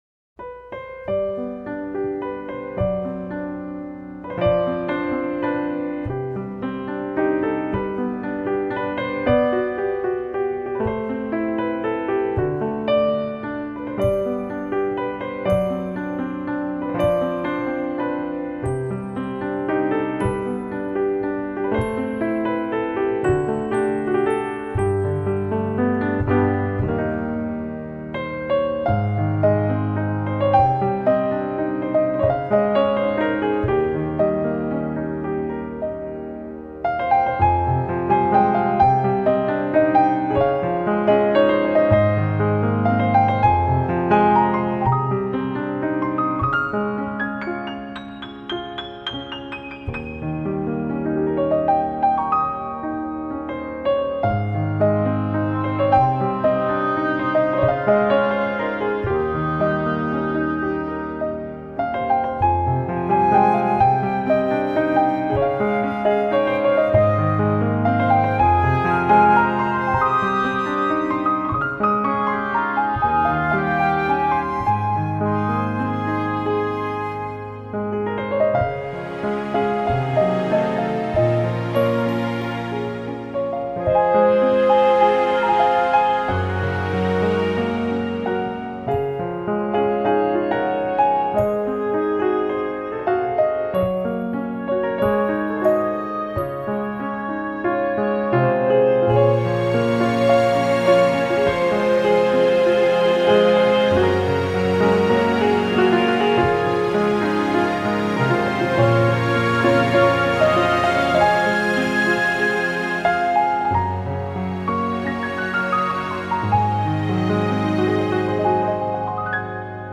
专辑语种：纯音乐专辑1CD
温柔、诗意、平易近人的钢琴旋律